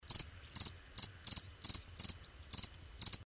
=Crickets
Category: Radio   Right: Both Personal and Commercial